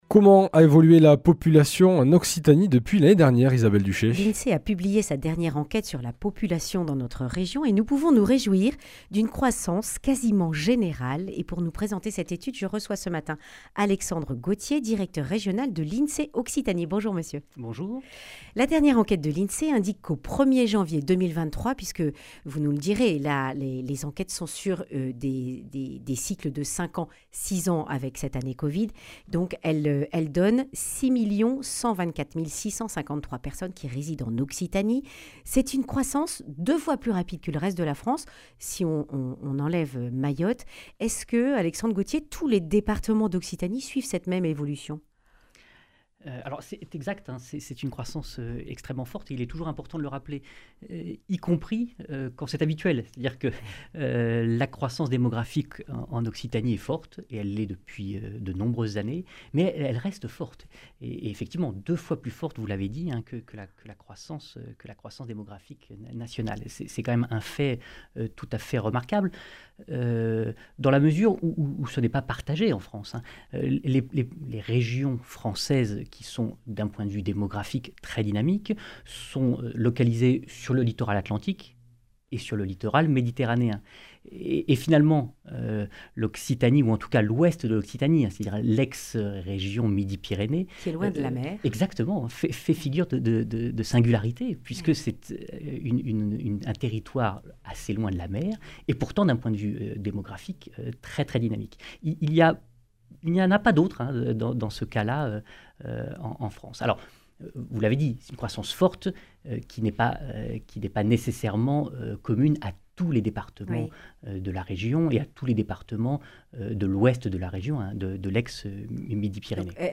Accueil \ Emissions \ Information \ Régionale \ Le grand entretien \ Comment évolue la population en Occitanie ?